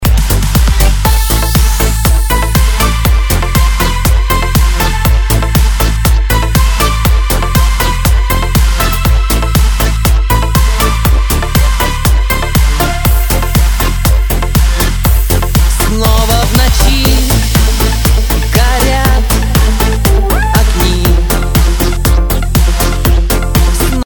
[Demo] - Щека к щеке ( Pop , Disco ) отрывок
Помогите, пожалуйста, определиться, что подхрипывает, и подхрипывает ли вообще? Перегруз, или тембр баса, например?